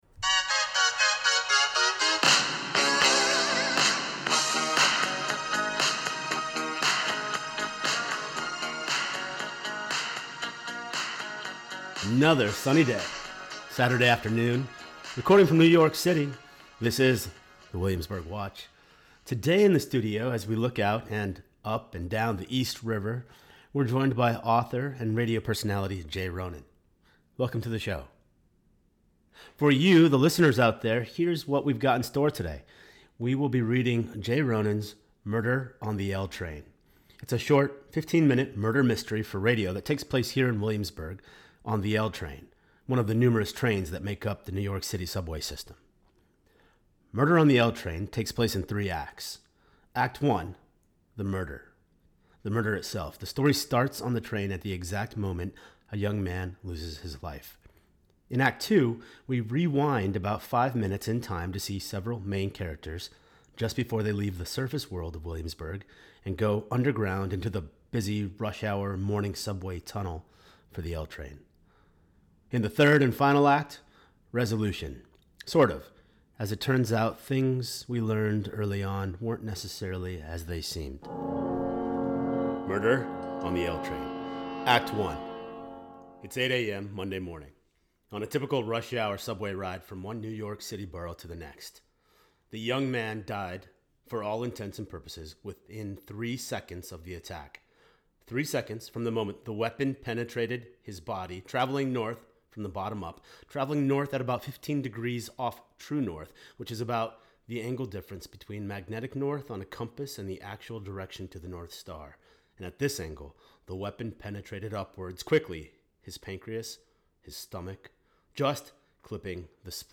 A SHORT RADIO SHOW MURDER MYSTERY: “Murder on the L Train”